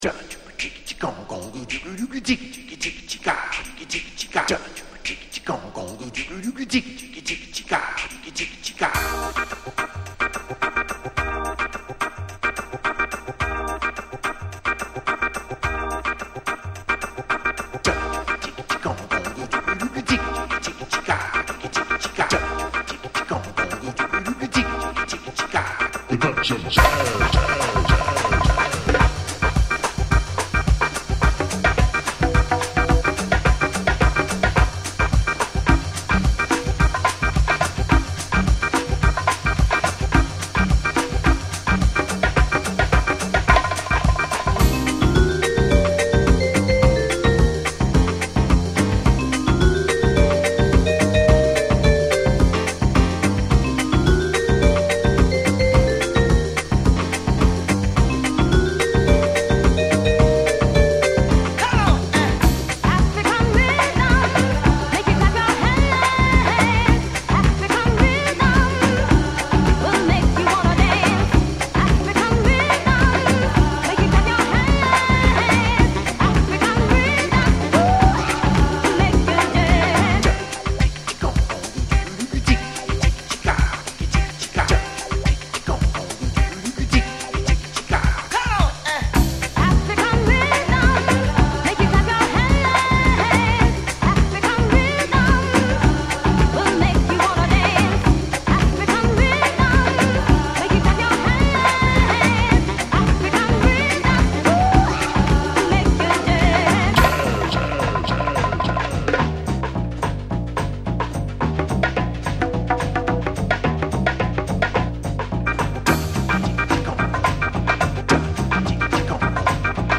disco mix
a touch of jazz version